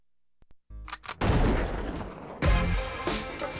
BLAST2.WAV